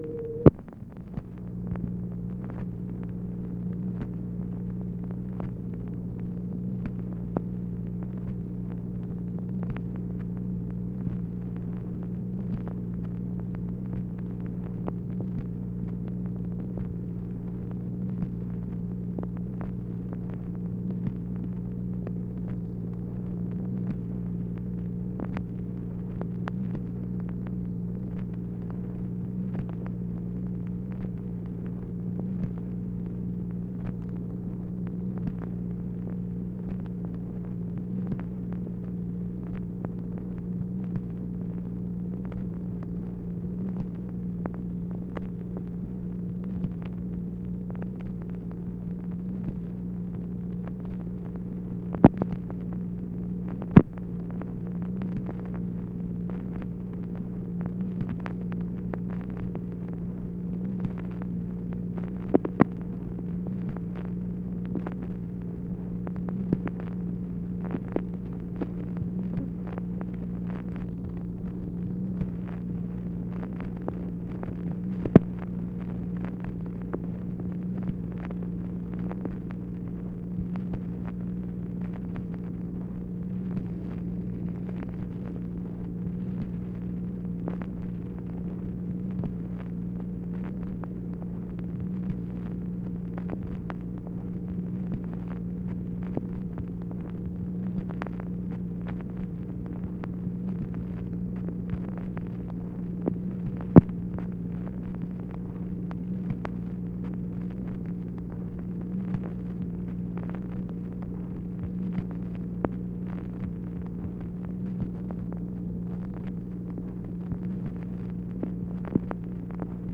MACHINE NOISE, February 7, 1964
Secret White House Tapes | Lyndon B. Johnson Presidency